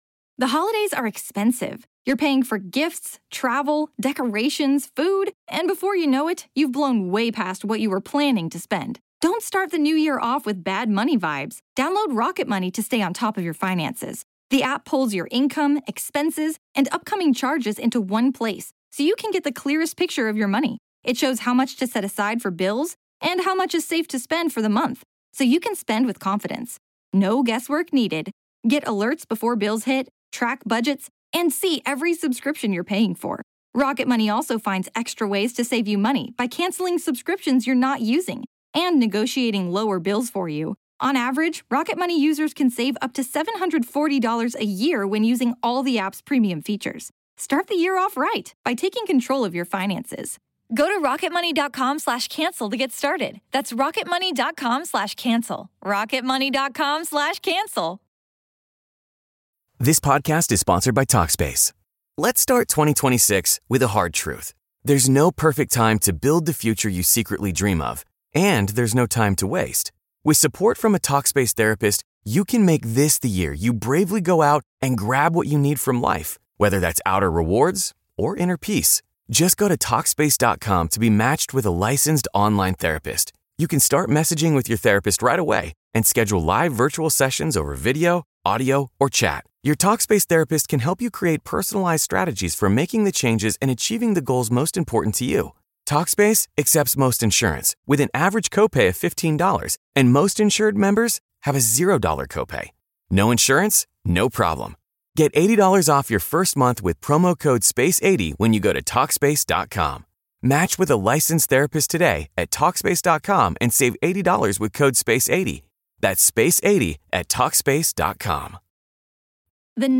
This is Part One of our conversation.